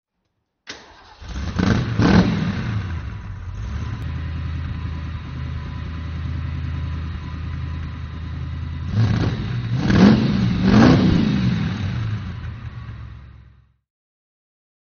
Motorsounds und Tonaufnahmen zu De Tomaso Fahrzeugen (zufällige Auswahl)
de Tomaso Mangusta (1970) - Starten
de_Tomaso_Mangusta_1970_-_Starten.mp3